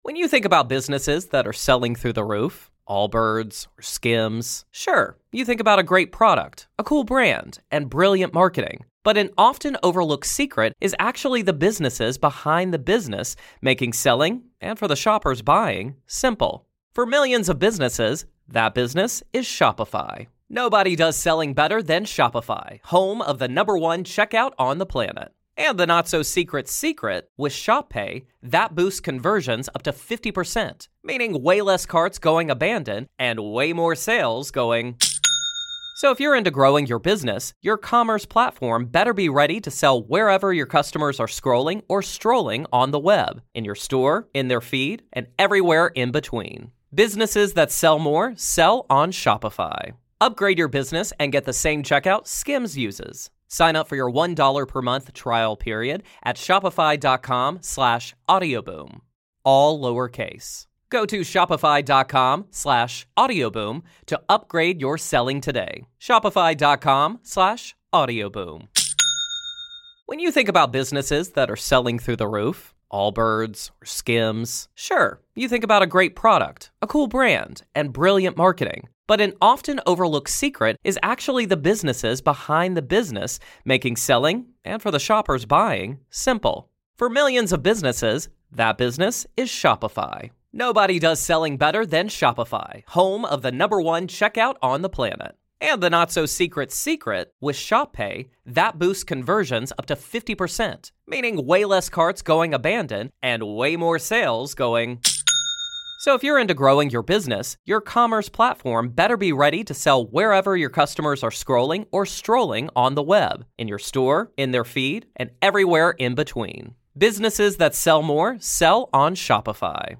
If you enjoy our interviews and conversations about "The Dead," why not listen ad-free?